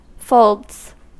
Ääntäminen
Ääntäminen US Haettu sana löytyi näillä lähdekielillä: englanti Käännöksiä ei löytynyt valitulle kohdekielelle. Folds on sanan fold monikko.